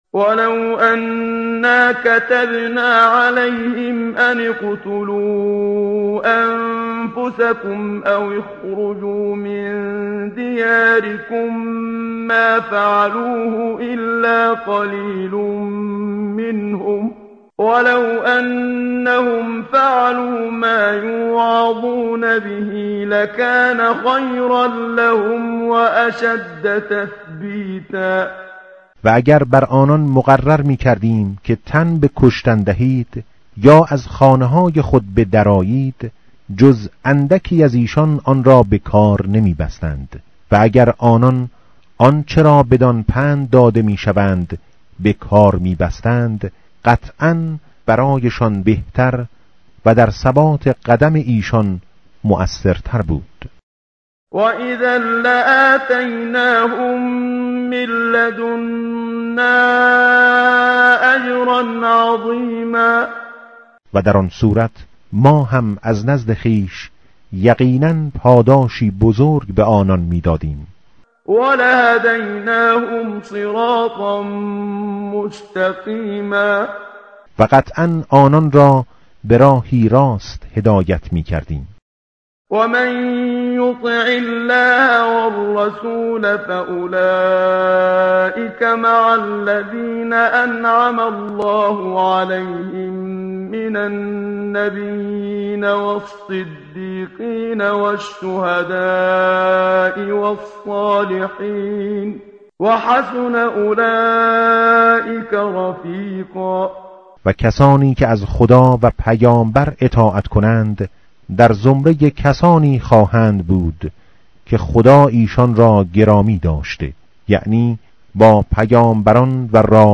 tartil_menshavi va tarjome_Page_089.mp3